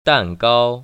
[dàngāo] 딴까오  ▶